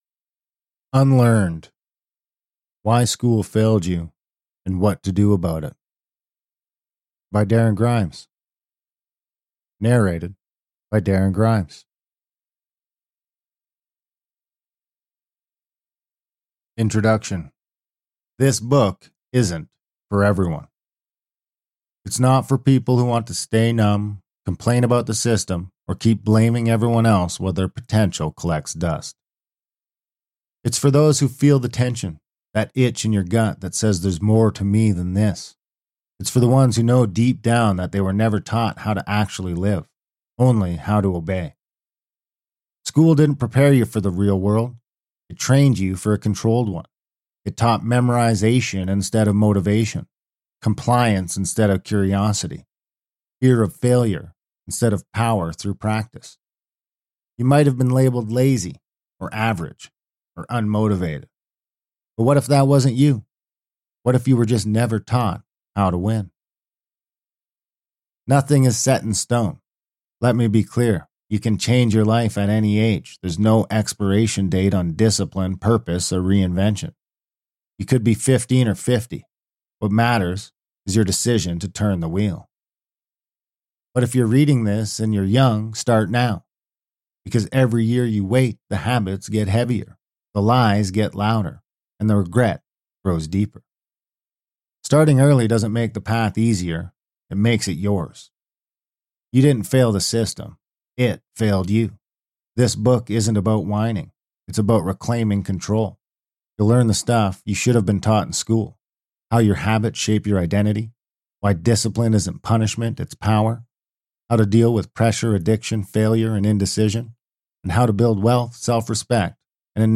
Unlearnedfullbook.mp3